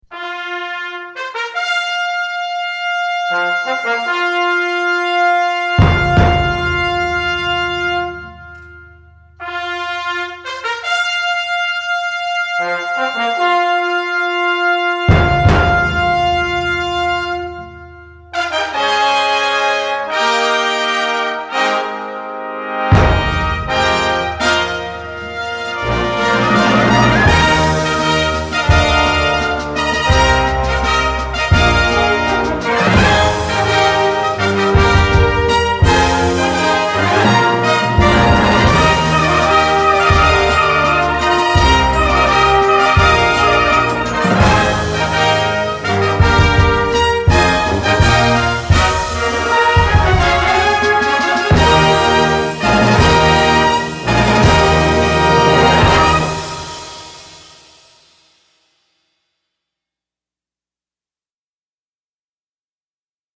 Besetzung: Blasorchester
dramatic fanfare